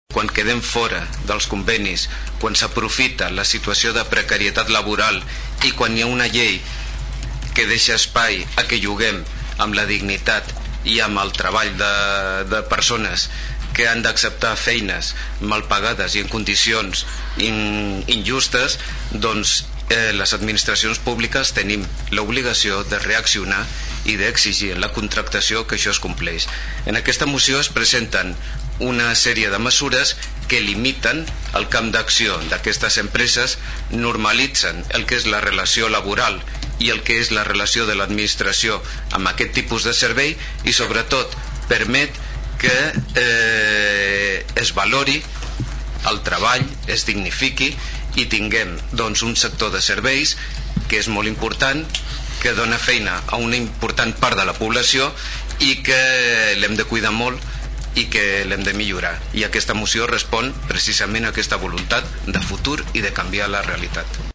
El ple de l’Ajuntament de Tordera aprova una moció al voltant de les empreses multiserveis
El regidor socialista Rafa Delgado va dir que la moció prové de l’esquerda que ha deixat la reforma laboral del PP, la qual ha produït un trencament de les relacions laborals.